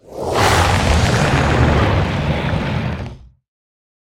snapshot / assets / minecraft / sounds / mob / warden / roar_5.ogg
roar_5.ogg